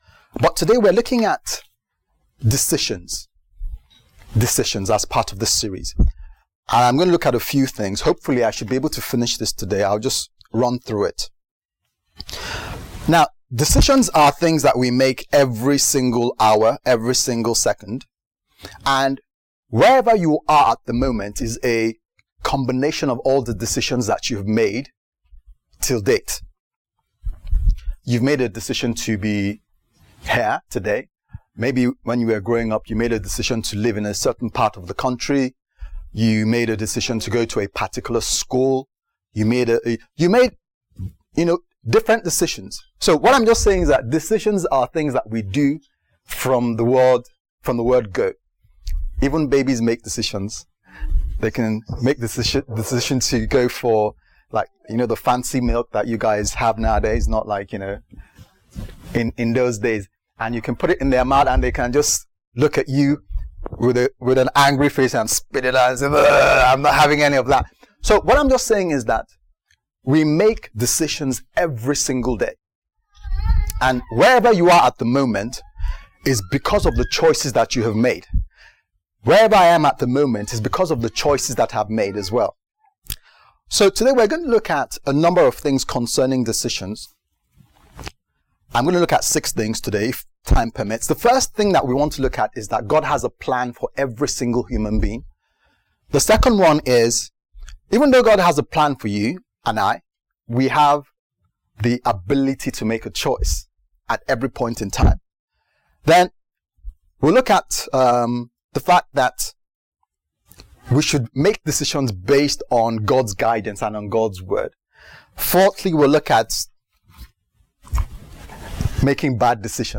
The True Perspective Service Type: Sunday Service « The True Perspective